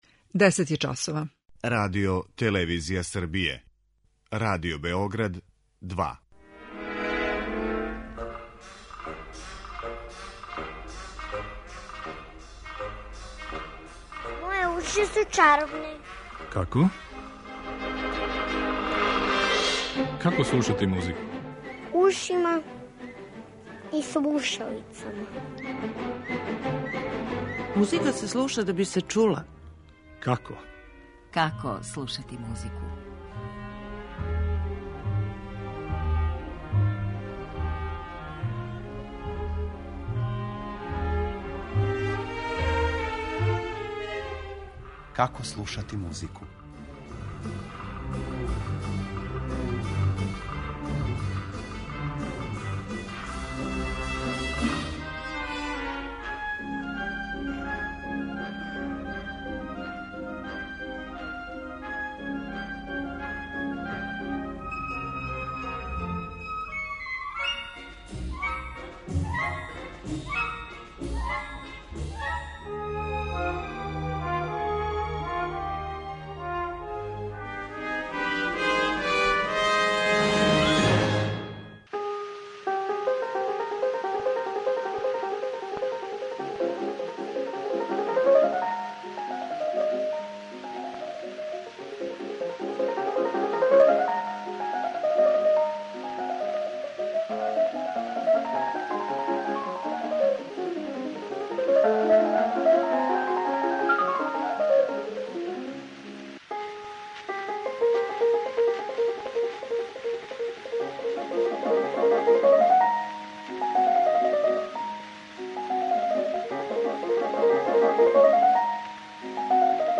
О овој теми говоре: музиколог